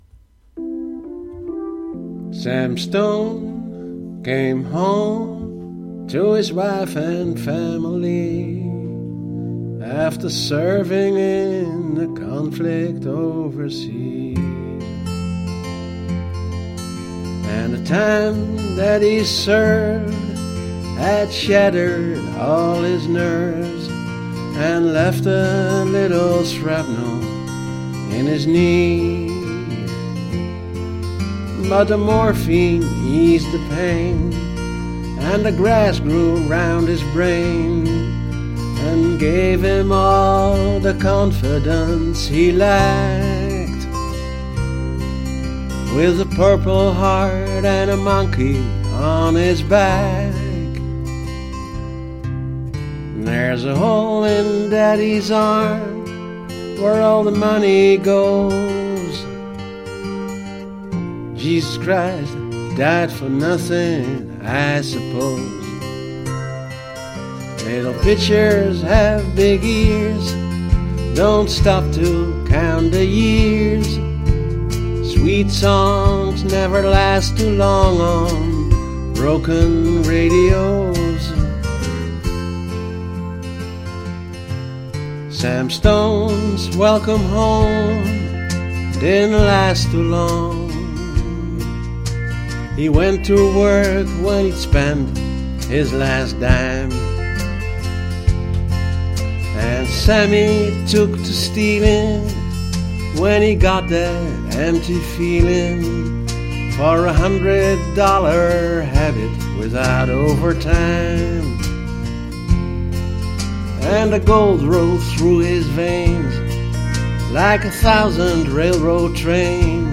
ditmaal sung by me